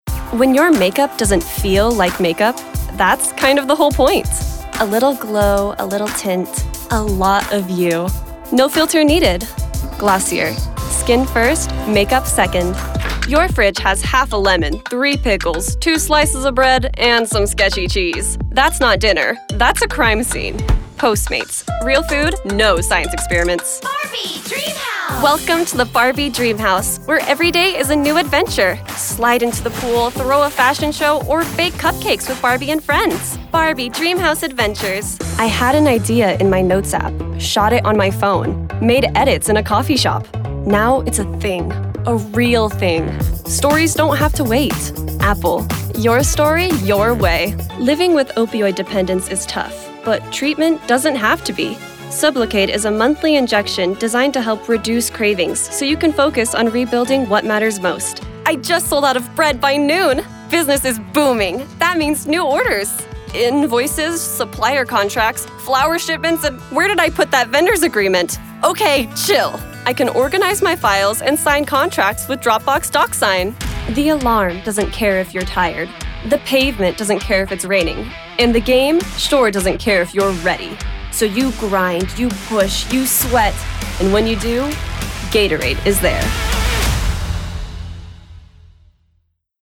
Female Voice Over Talent
Demos